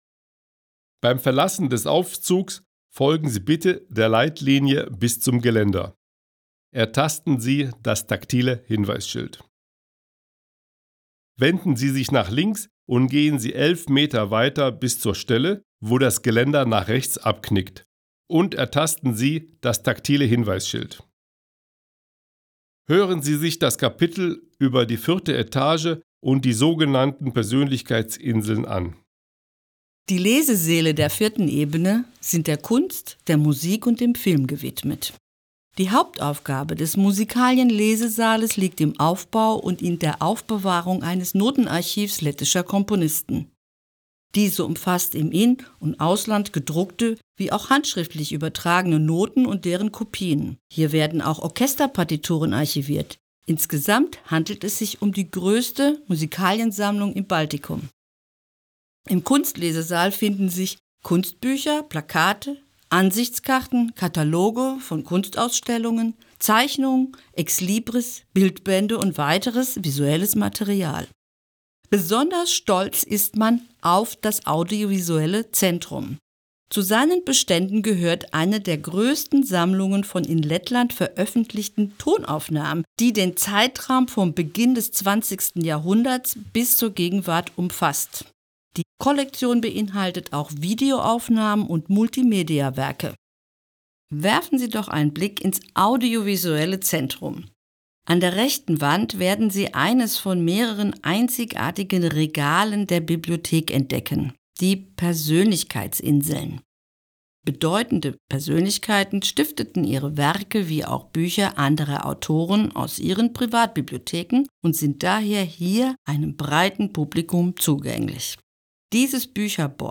Tūrisma gidi